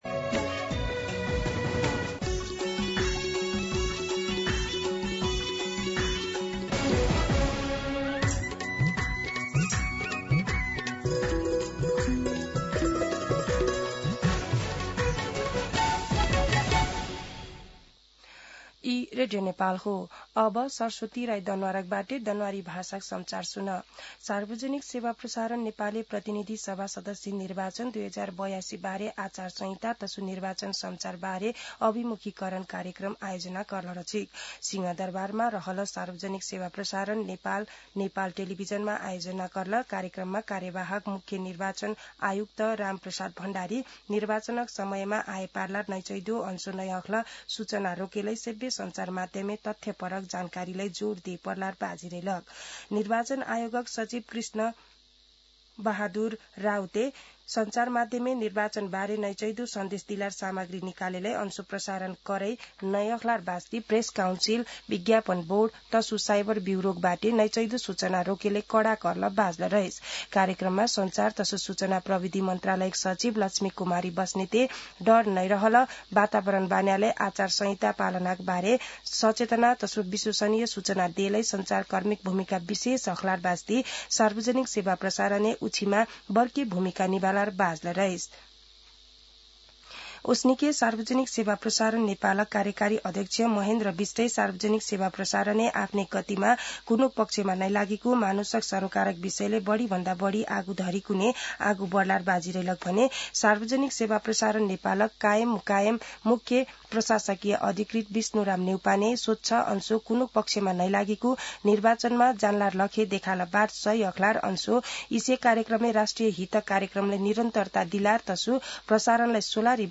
An online outlet of Nepal's national radio broadcaster
दनुवार भाषामा समाचार : १२ माघ , २०८२
Danuwar-News-10-12.mp3